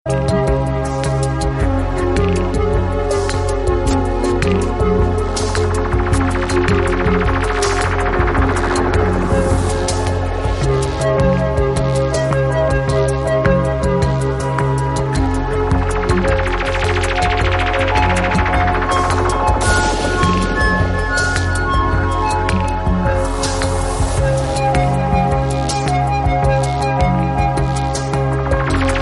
Kategorie Elektroniczne